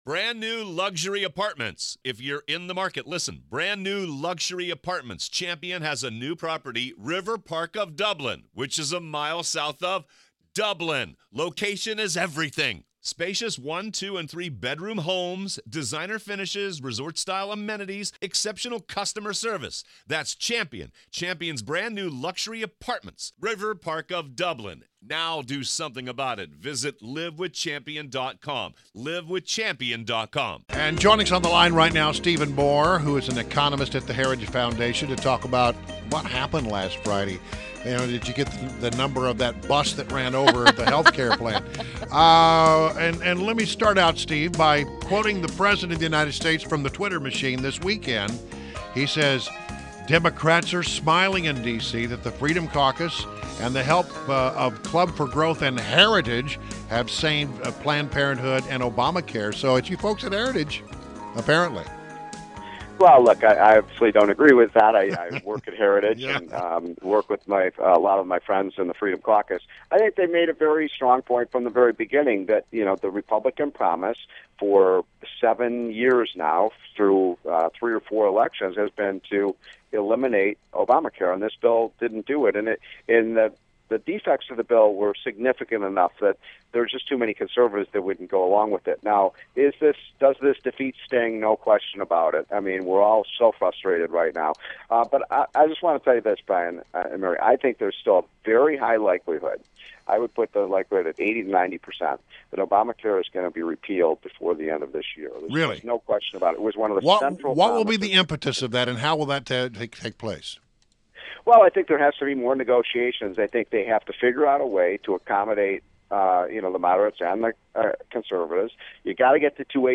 INTERVIEW — STEVE MOORE – Economist at The Heritage Foundation – shared his thoughts on the health care vote fallout and Trump’s new office for innovation.